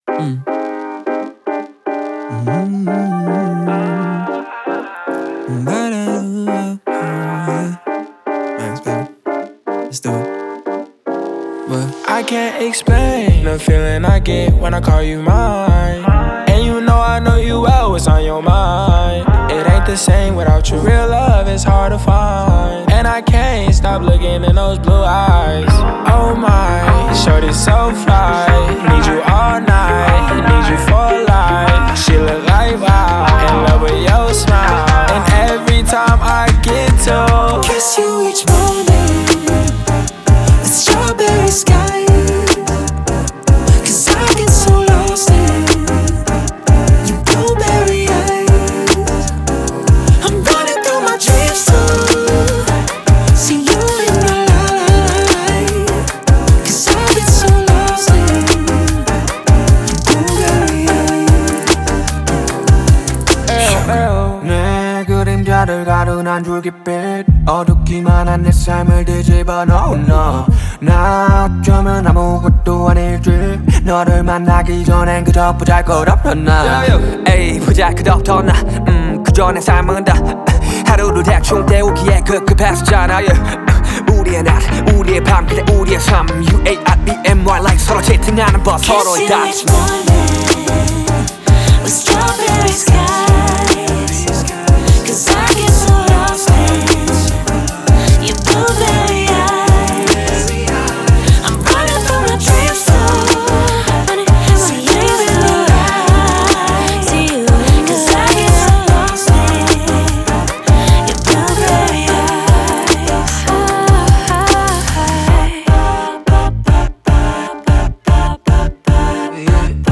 это яркая и мелодичная песня в жанре поп-рэп